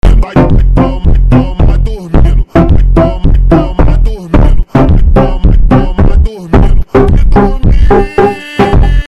Analogue Horror Funk. Botón de Sonido